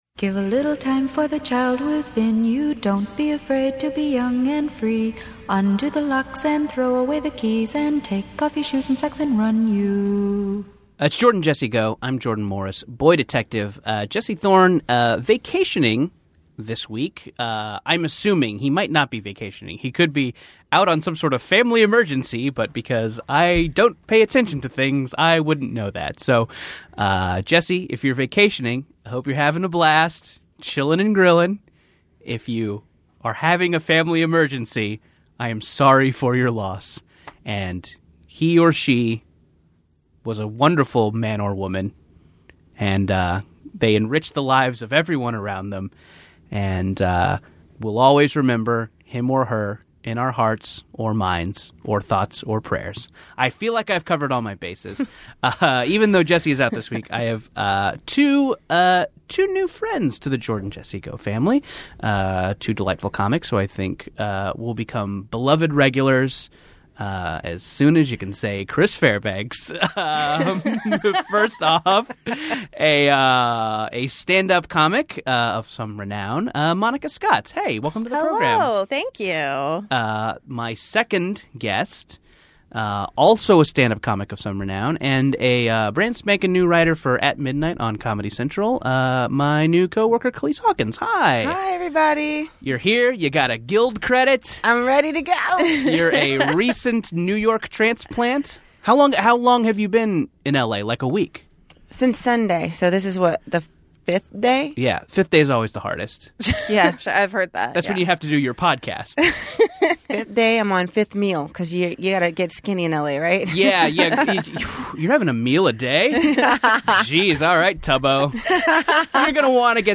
Society & Culture, Comedy, Tv & Film